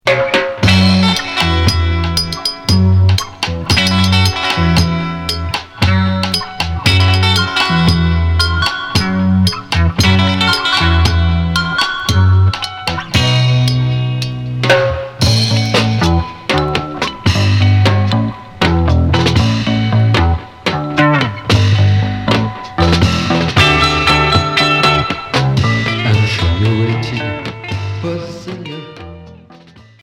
Rock Unique Maxi 45t